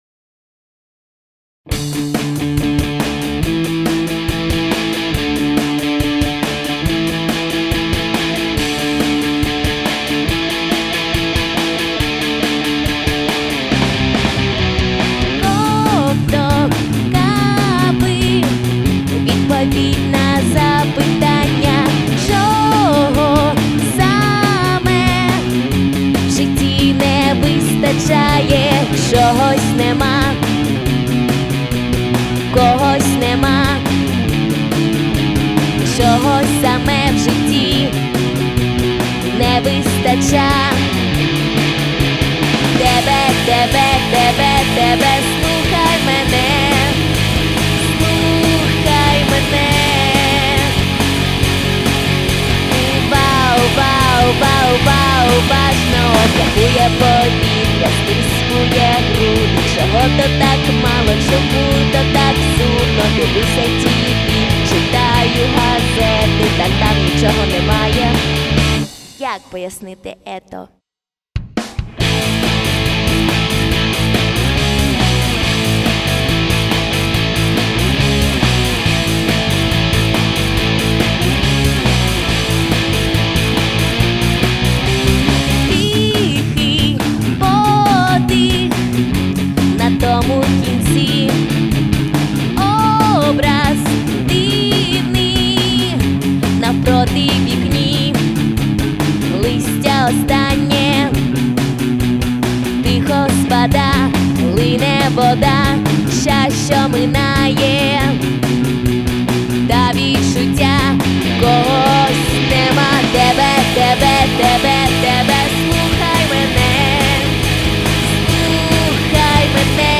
гитара
бас-гитара
вокал
бубен, барабаны
Стиль исполнения –  альтер-поп.